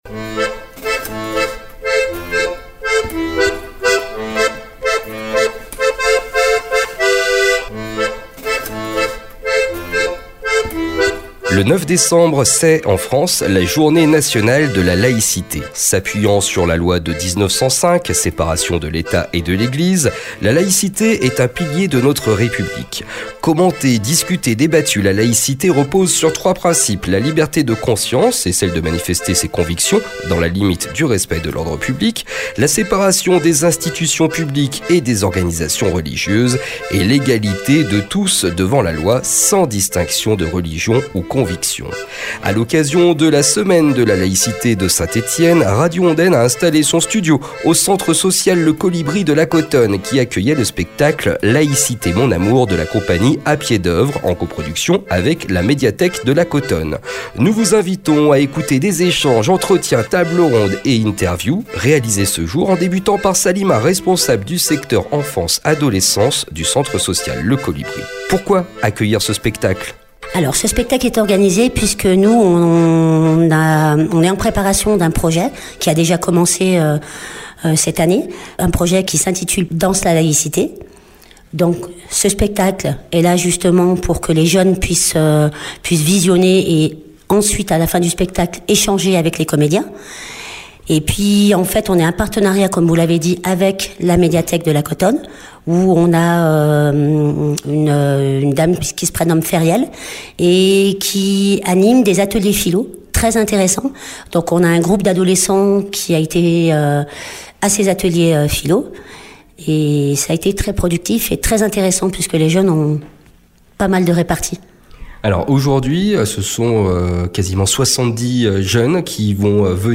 Nous vous invitons, aujourd’hui, à 13h puis à 17h, une série d’entretiens, d’échanges, de tables rondes et discussions autour du spectacle, de la Laïcité et, plus largement, du Vivre Ensemble.
La laïcité implique la neutralité de l’Etat et impose l’égalité de tous devant la loi sans distinction de religion ou conviction. A l’occasion de la Semaine de la Laïcité de Saint-Etienne, Radio Ondaine a installé son studio au Centre Social Le Colibri, qui avec la médiathèque de la Cotonne, organisaient une représentation du spectacle, Laïcité Mon Amour, de la Compagnie A Pied d’Œuvre.